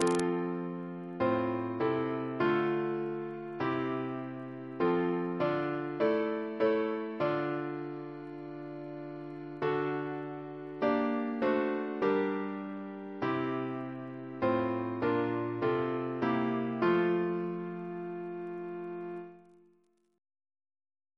Double chant in F Composer: William Crotch (1775-1847), First Principal of the Royal Academy of Music Reference psalters: ACB: 137